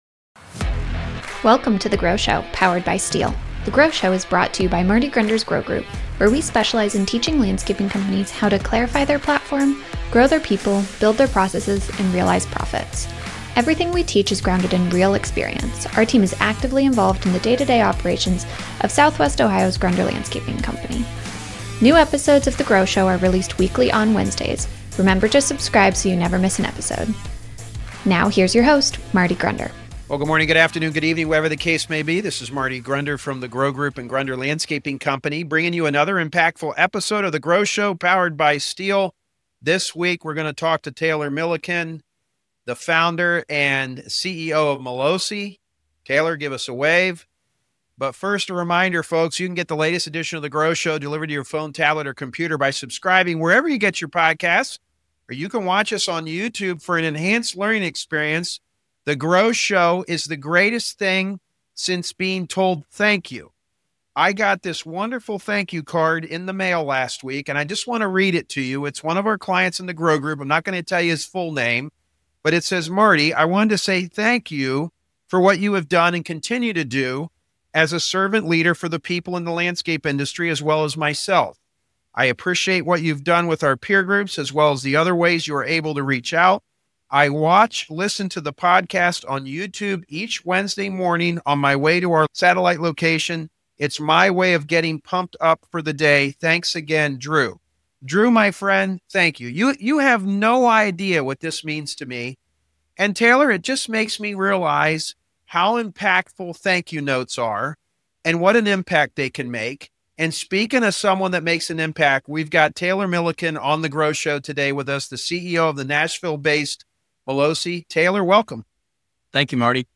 GROW Host Interview Series